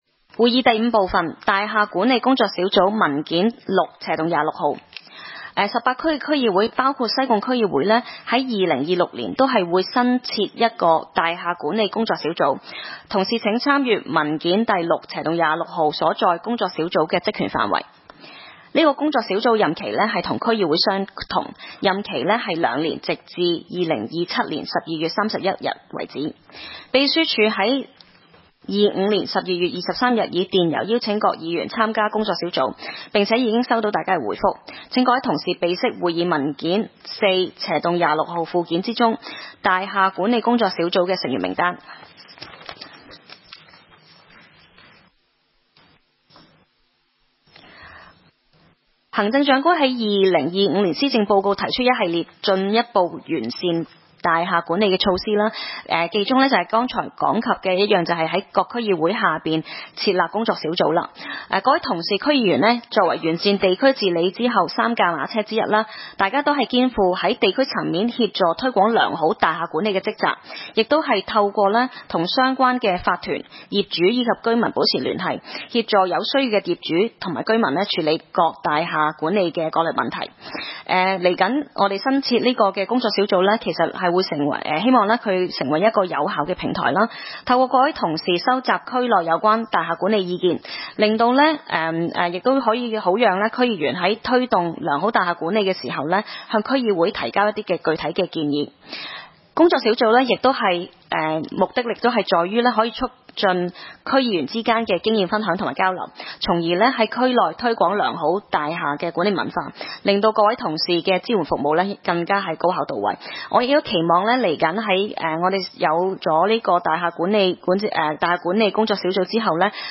區議會大會的錄音記錄
西貢區議會第一次會議
西貢將軍澳政府綜合大樓三樓